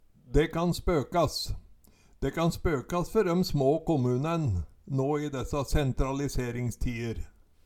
Høyr på uttala Ordklasse: Uttrykk Kategori: Uttrykk Attende til søk